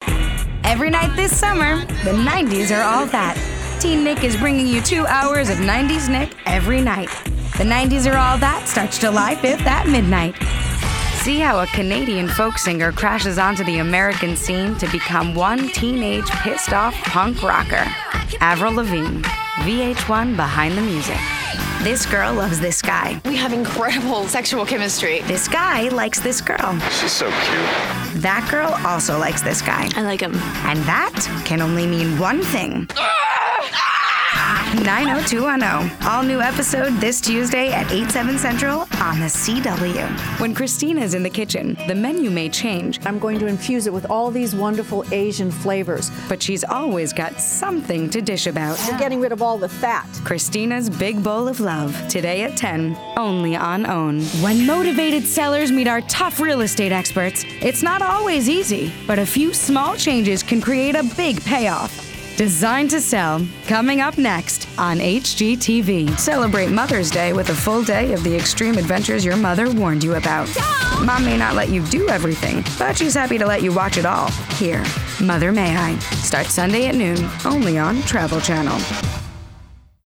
Commercial Reel